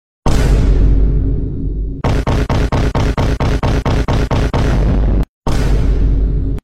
Repeated Vine Boom Sound Button - Free Download & Play